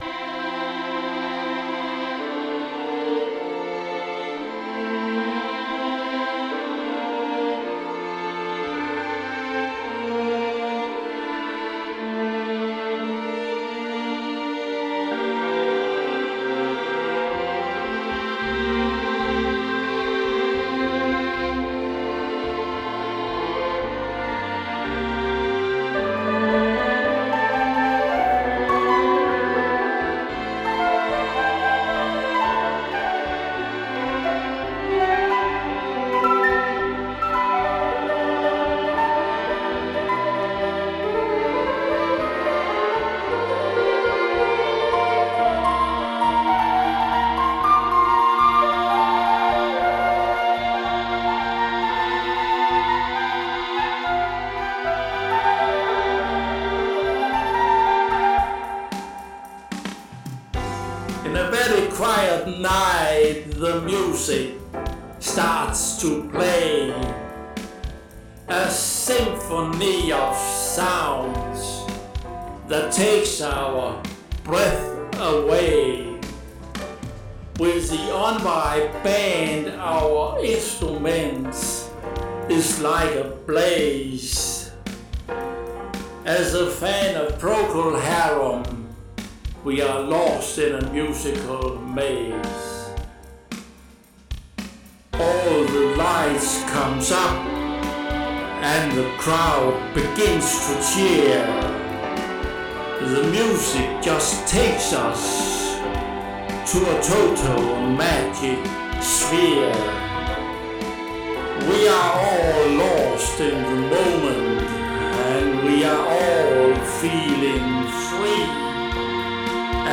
Genre: Orchestral Rock